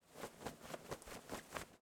movimiento.wav